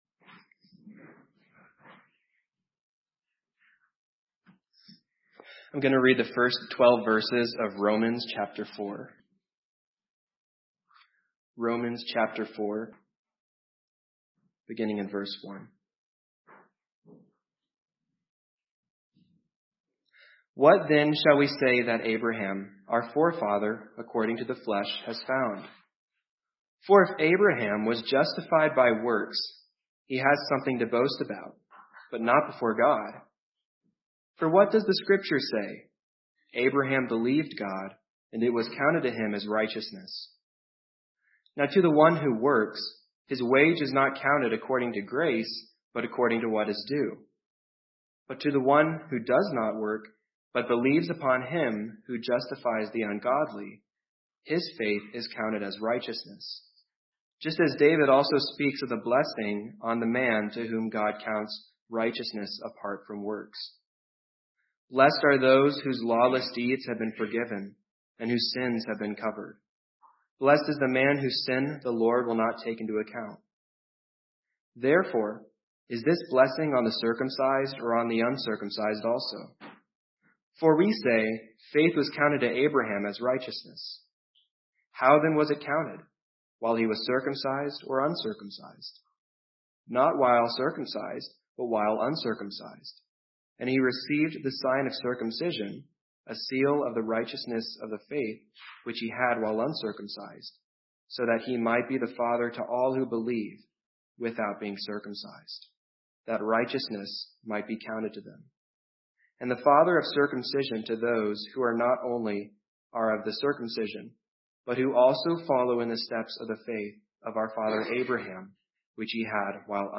Psalm 32 Service Type: Morning Worship Service Psalm 32 Are You the Blessed Man?